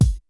HOTKICK3.WAV